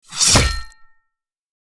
Clashing, Clanging and Resonating, from squeaky gates to hard metal impacts!
Guillotine-blade-drop-impact-hit.mp3